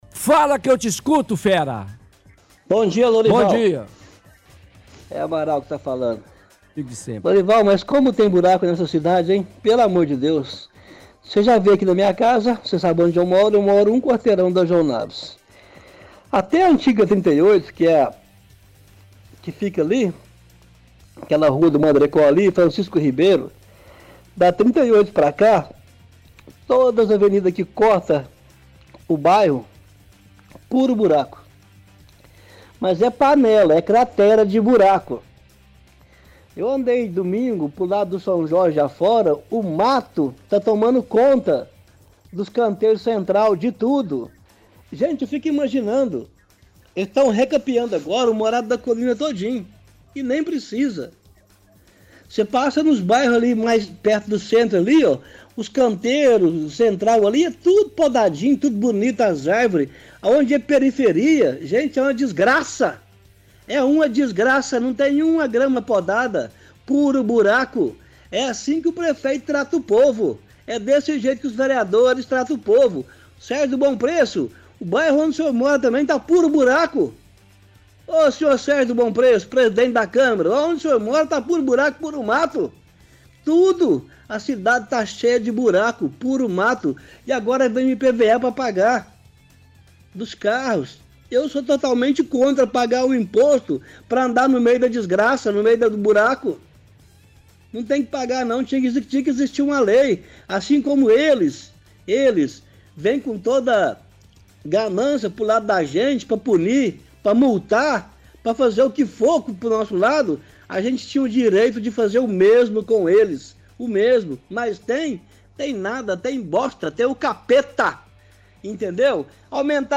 Home / Rádio / Pinga fogo – Buracos